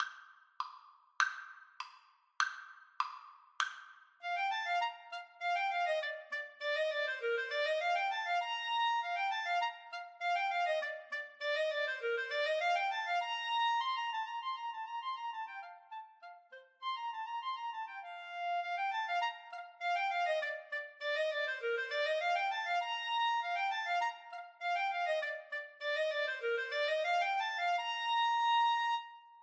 2/4 (View more 2/4 Music)
Allegro (View more music marked Allegro)
Classical (View more Classical Clarinet Duet Music)